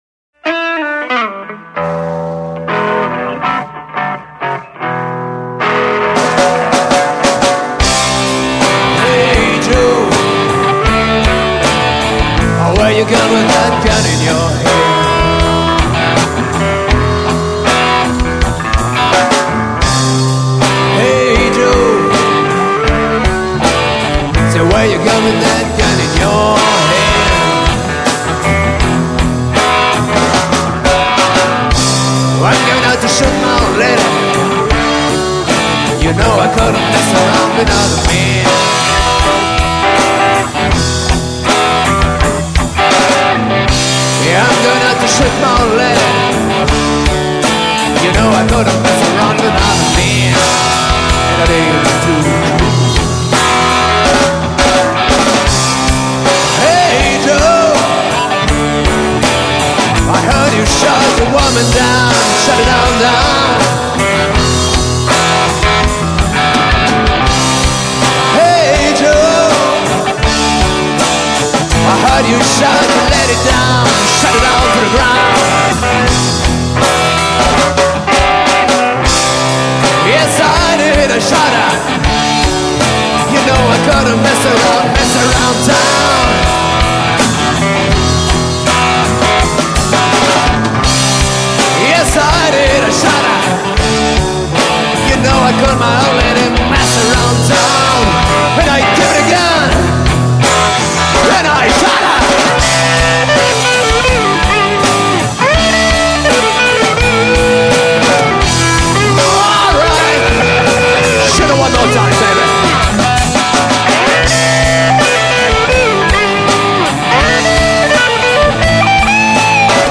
un groupe de reprises rock belge